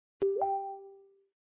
Звуки уведомлений о сообщениях
Оригинальный звук нового уведомления в Facebook